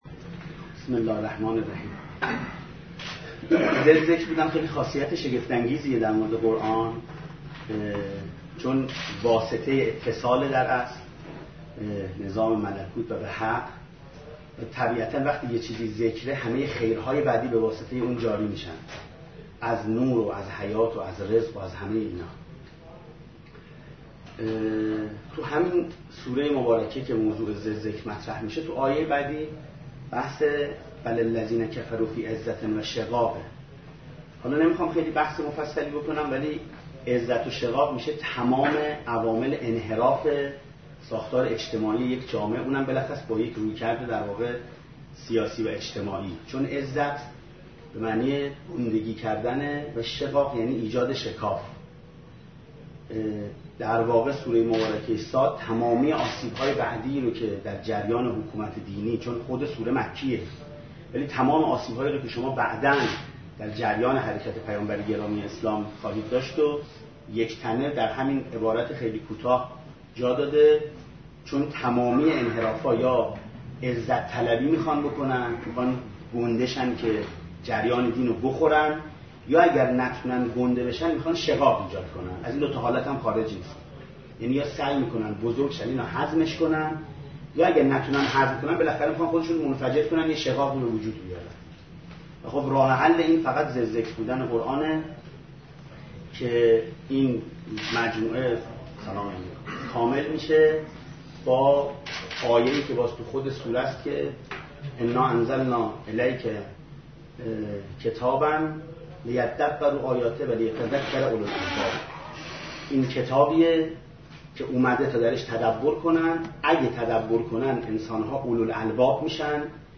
🔰 گزارش مشروح نشست خبری مجمع مدارس دانشجویی قرآن و عترت علیهم السلام دانشگاه تهران با خبرگزاری فارس منتشر شد.